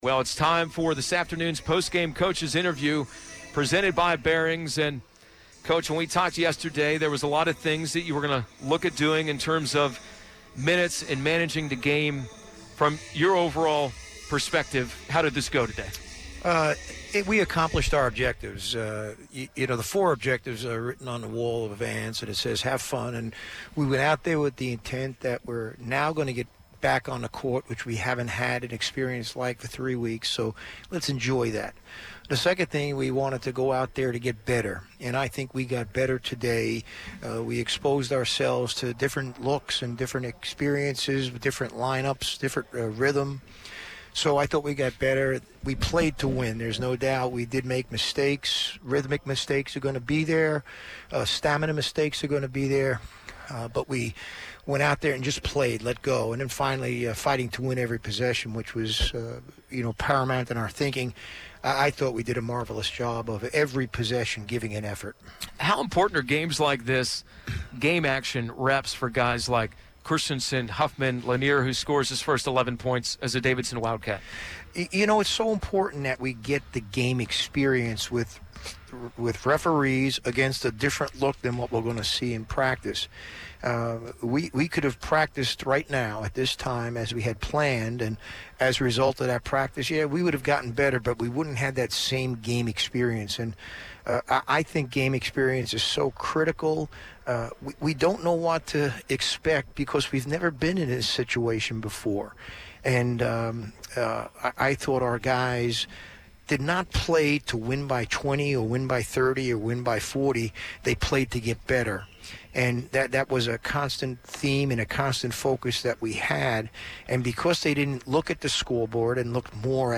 McKillop Postgame Radio Interview